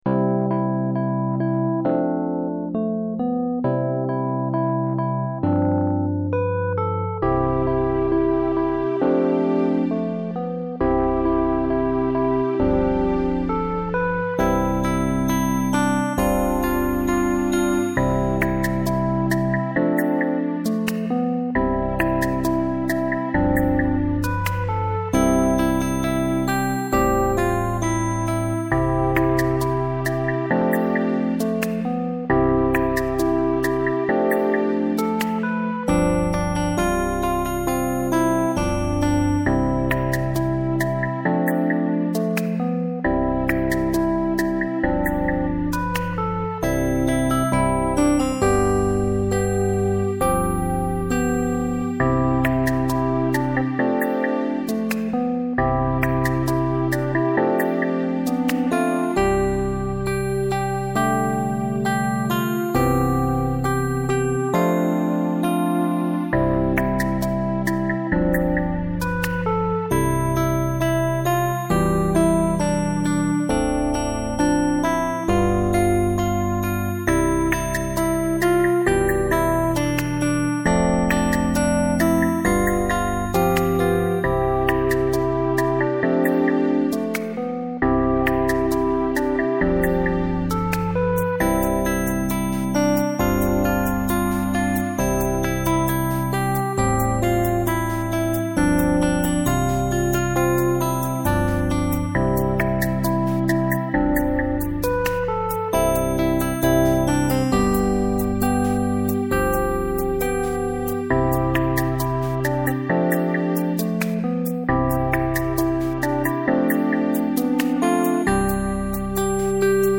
la base in C ;